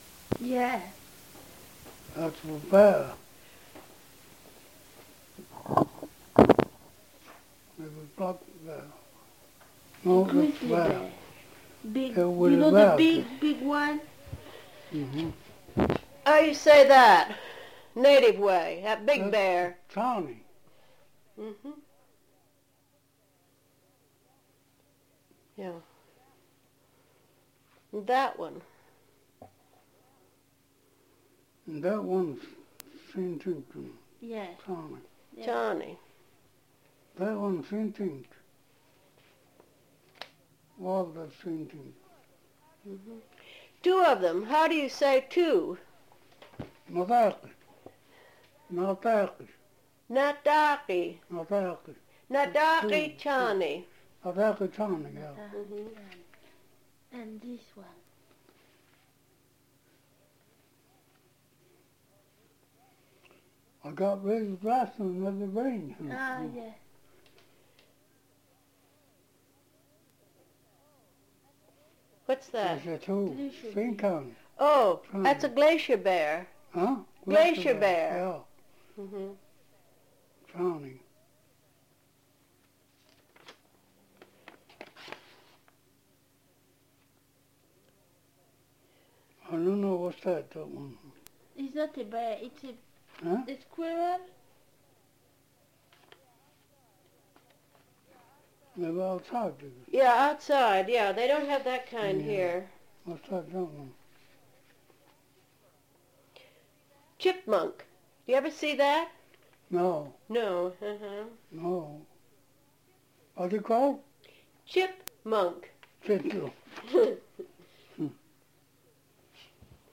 The interview was recorded in Copper Center, Alaska.
Summary: Anthropological interview conducted in Copper Center, Alaska.